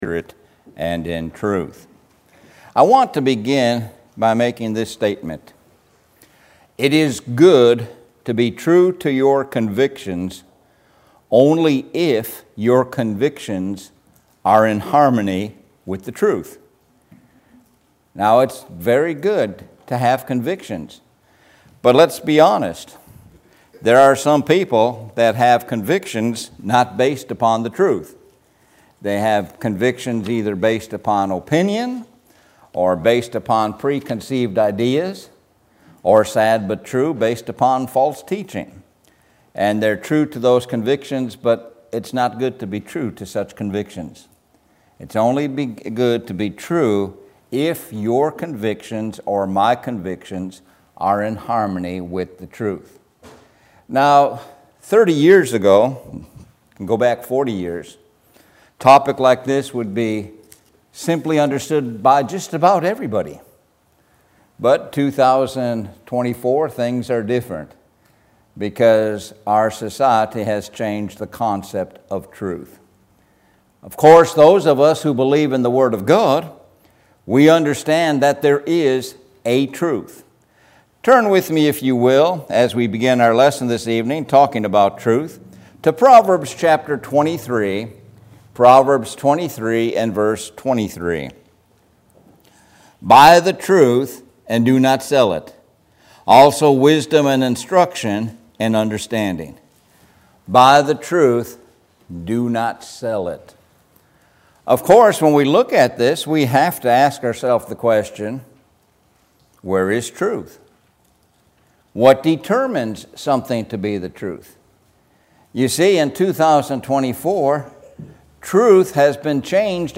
Sun PM Bible Sermon